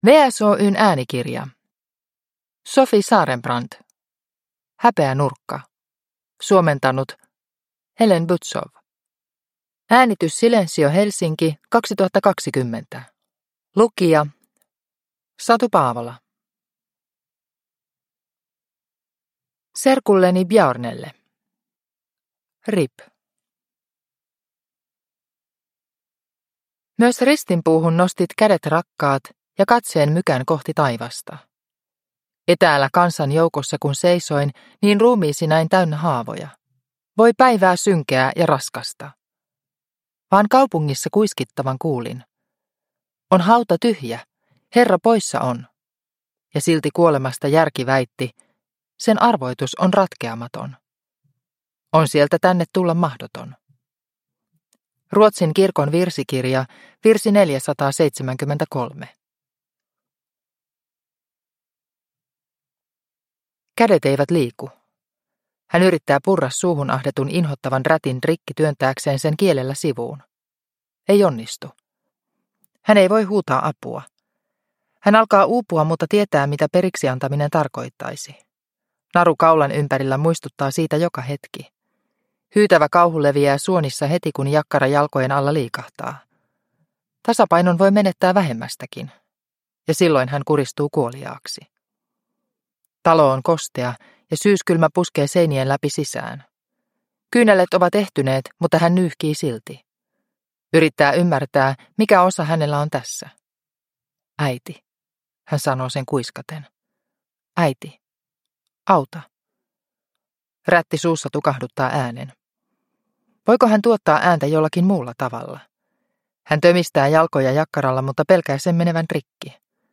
Häpeänurkka – Ljudbok – Laddas ner